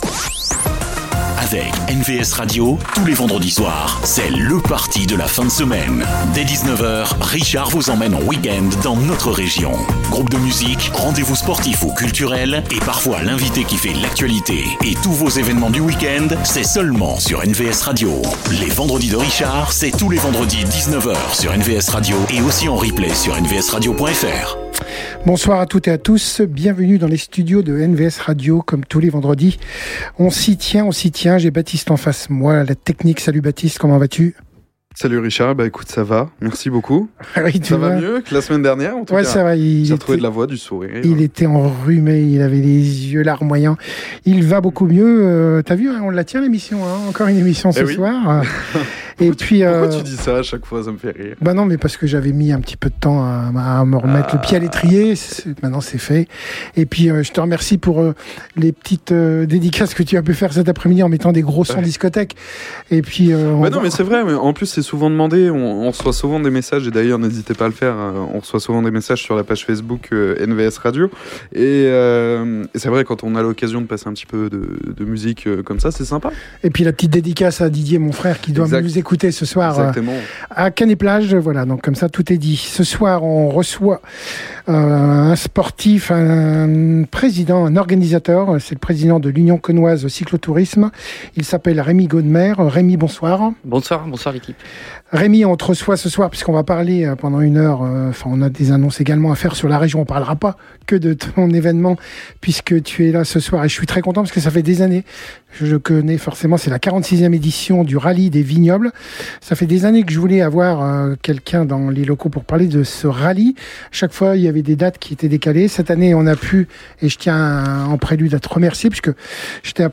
Une belle discussion autour du club, de ses projets, et bien sûr du Rallye des Vignobles 2025 qui aura lieu les 11 et 12 octobre à Cosne-Cours-sur-Loire.